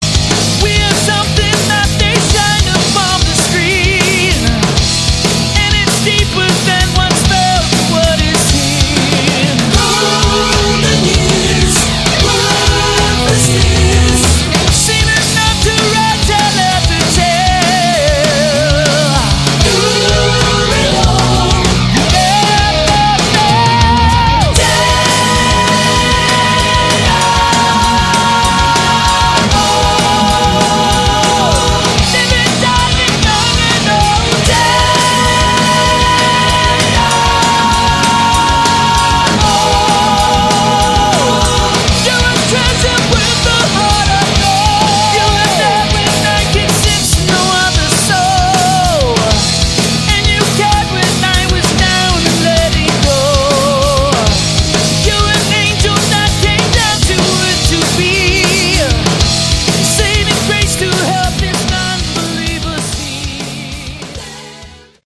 Category: CCM Hard Rock